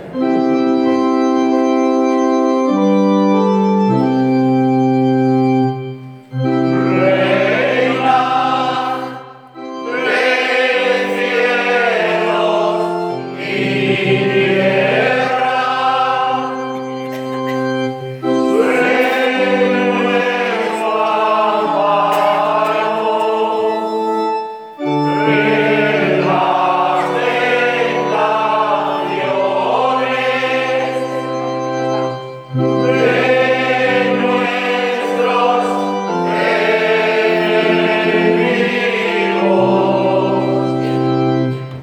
CANTADA
Grabaciones hechas en la Ermita durante la celebración de las Novenas del año 2019/2022. Canta el Pueblo.